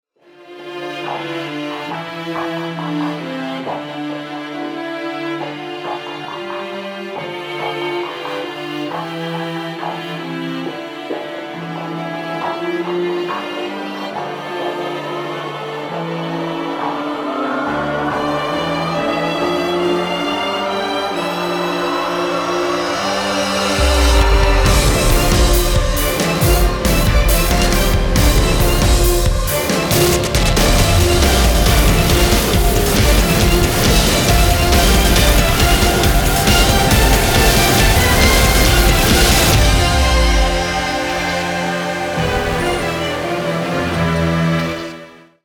• Качество: 320, Stereo
мощные
саундтреки
пугающие
страшные
Industrial
жуткие
зловещие